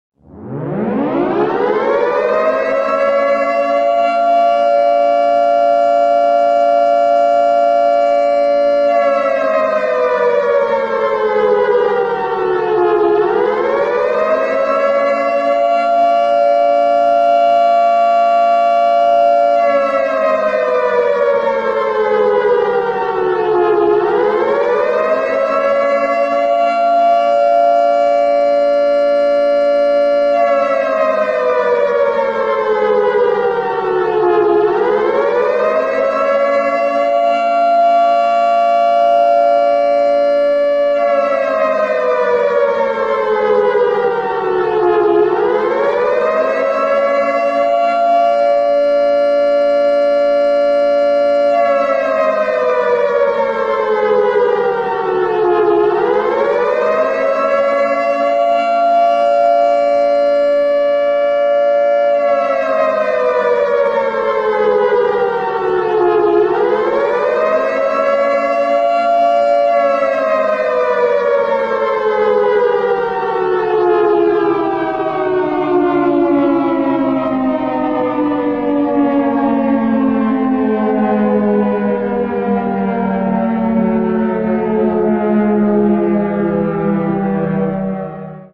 Som de Sirene Nuclear Brasil
Som de Alerta Vermelho Toque para Celular Alarme Nuclear
Categoria: Sons de sinos e apitos
som-de-sirene-nuclear-brasil-pt-www_tiengdong_com.mp3